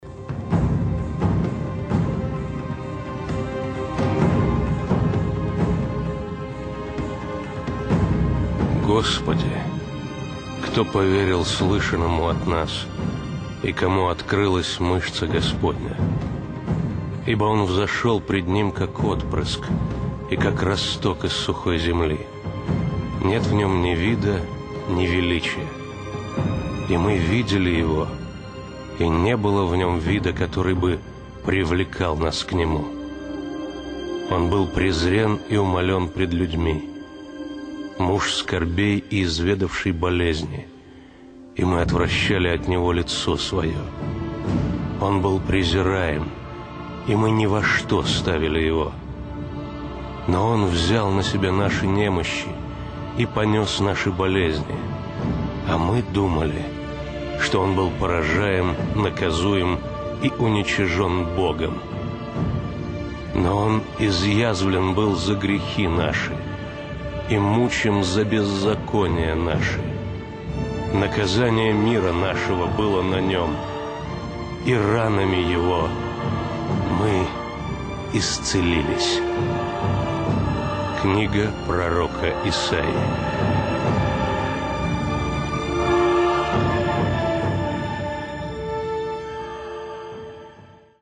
Отрывок начитан каким-то диктором, но настолько сильно, что аж мороз по коже...